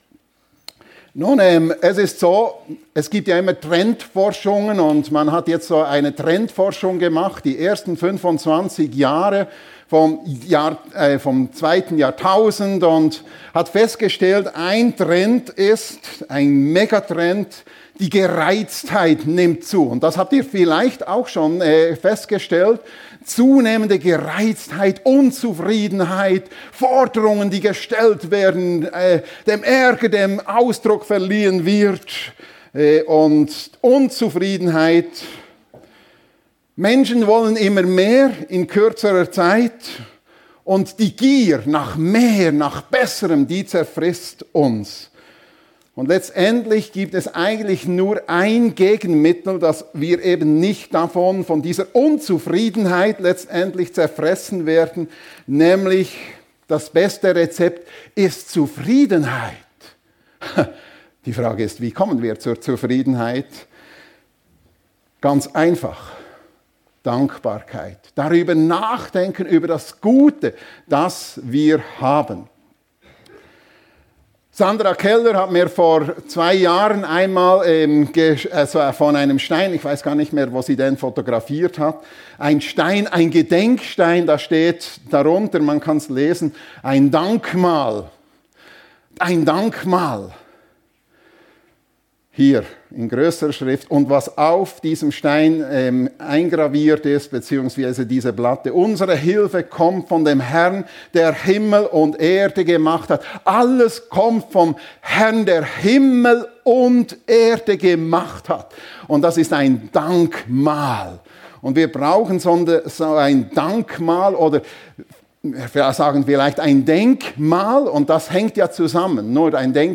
Denk-mal, Dank-mal ~ FEG Sumiswald - Predigten Podcast